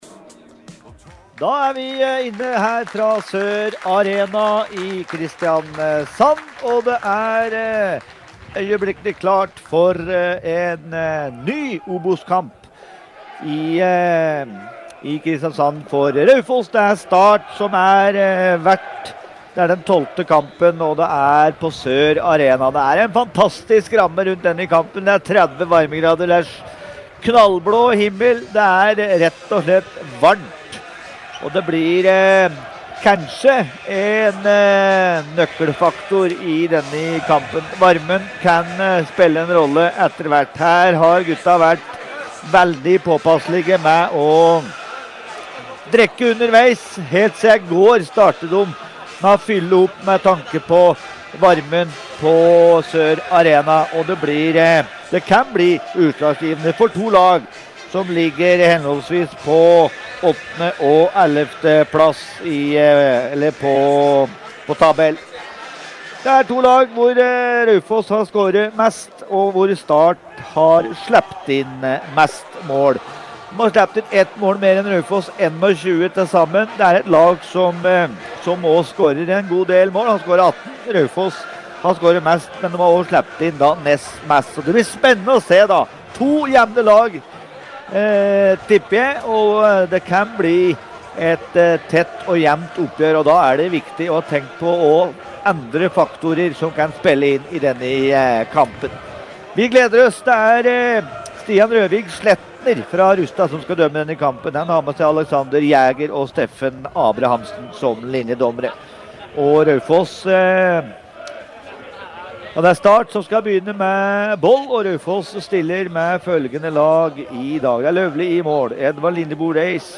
Kampene sendes på radioens FM-nett og på OA-TV.